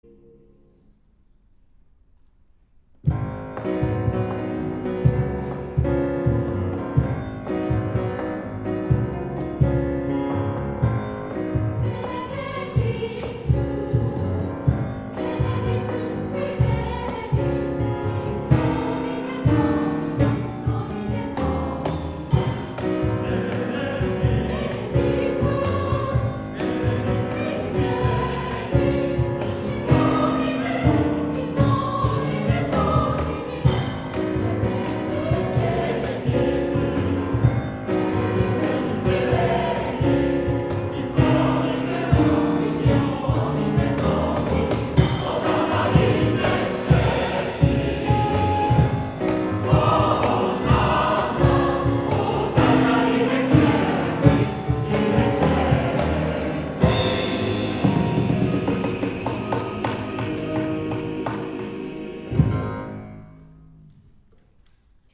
参考音源を付けましたので、リズム感の参考にして下さい。